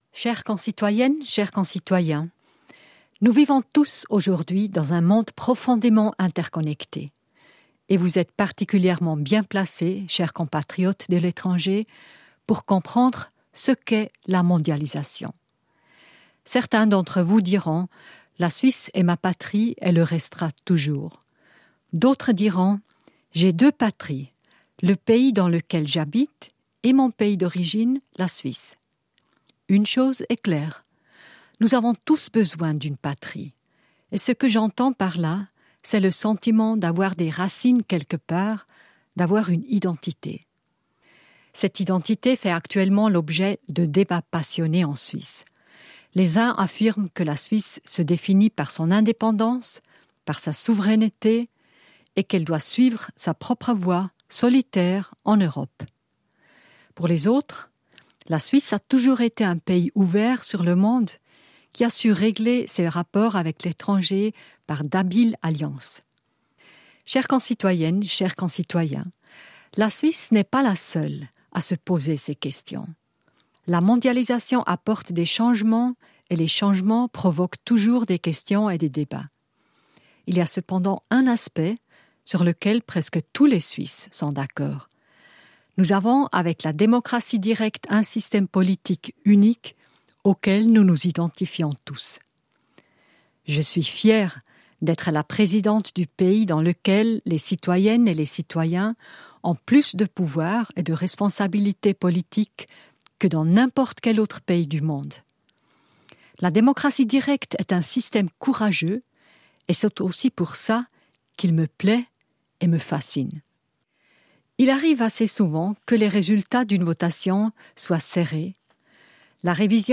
Allocution de la présidente de la Confédération Simonetta Sommaruga